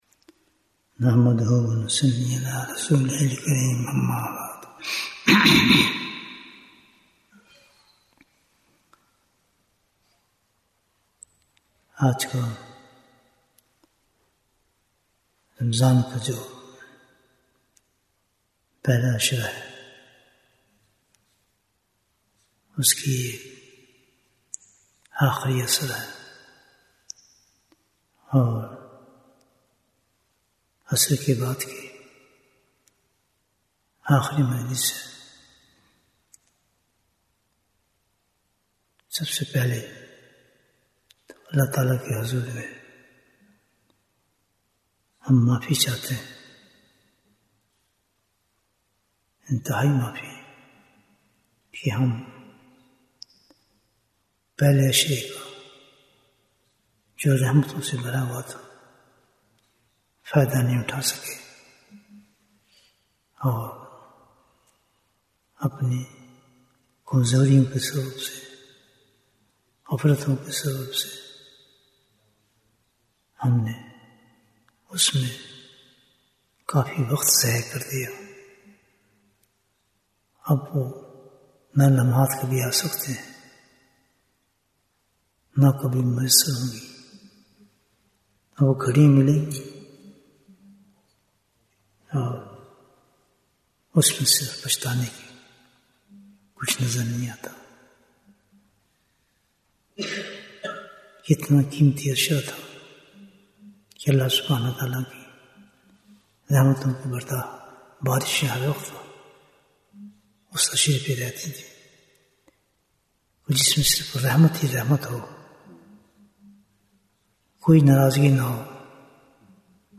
Bayan, 50 minutes 10th March, 2025 Click for English Download Audio Comments Jewels of Ramadhan 2025 - Episode 13 We seek forgiveness from Allah ta'ala for not taking benefit of the first ten days of Ramadan due to our negligence, laziness and ignorance.